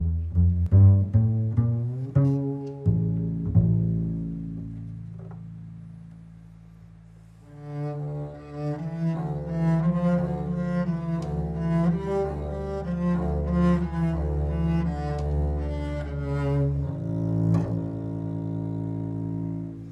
Audición de diferentes sonidos de la familia de cuerda frotada.
Contrabajo